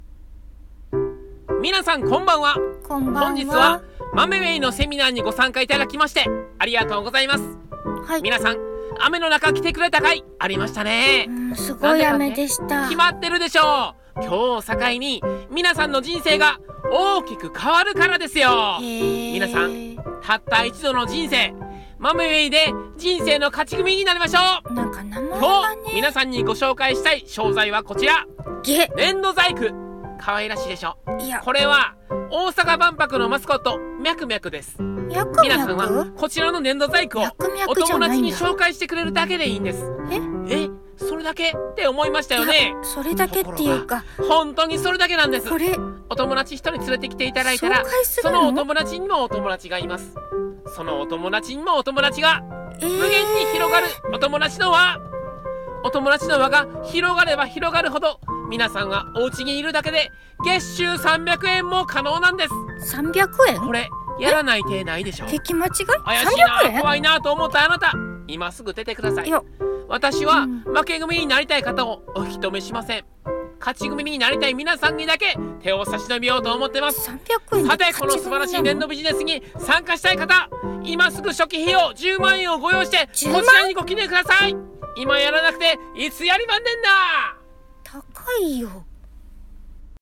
一人声劇「Mameway〜粘土ビジネスで勝ち組に！」